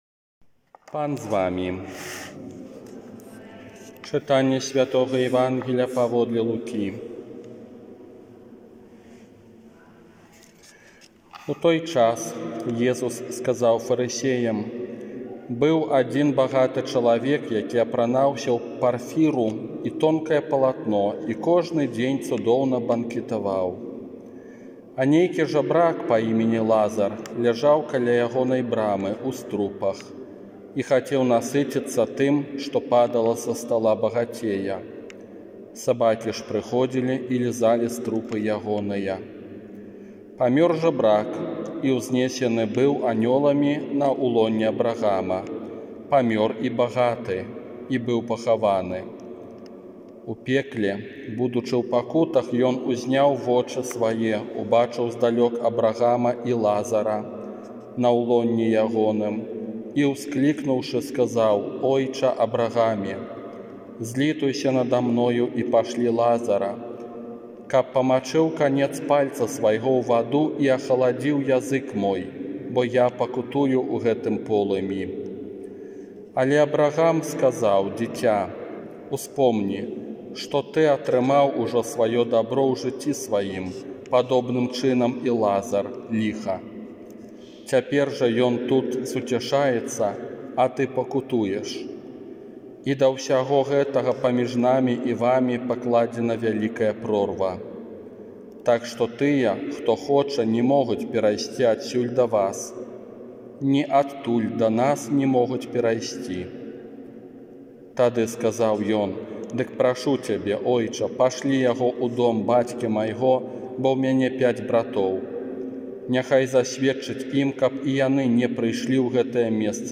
ОРША - ПАРАФІЯ СВЯТОГА ЯЗЭПА
Казанне на дваццаць шостую звычайную нядзелю